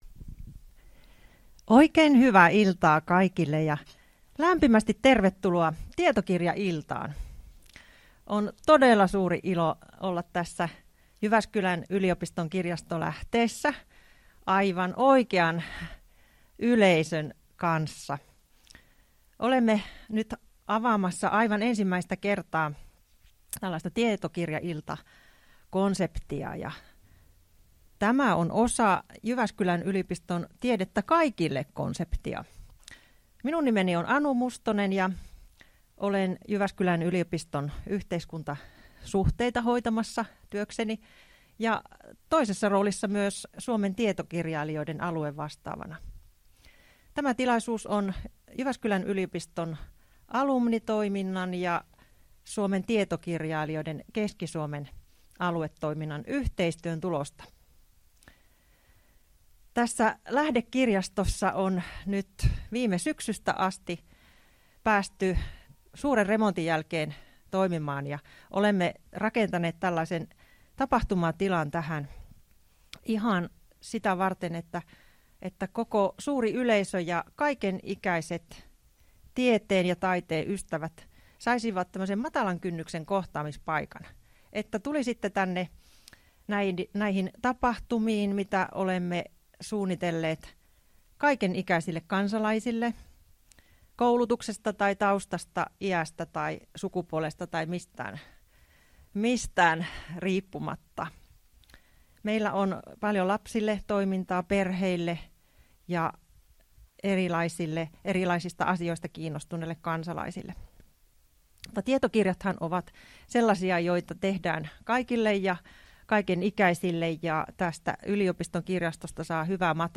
Tallenne Lähde-kirjastolla pidetystä tietokirjaillasta